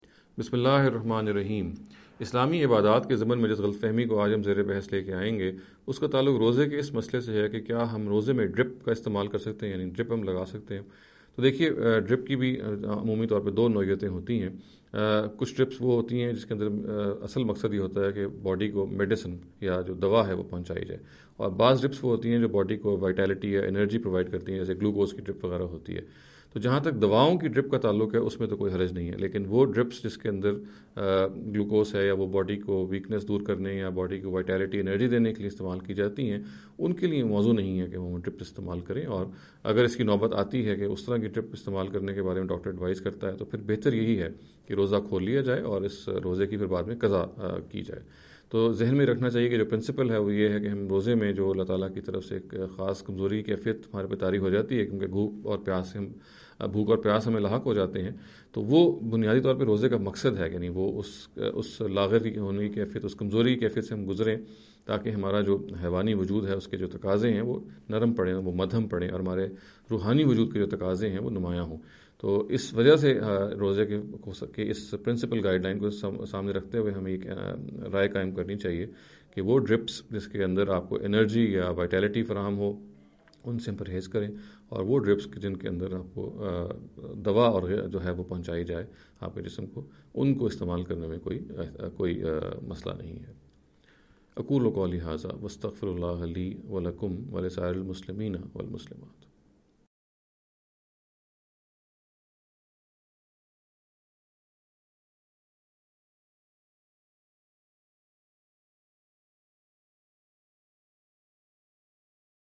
This lecture series will deal with some misconception regarding the Islamic Worship Ritual.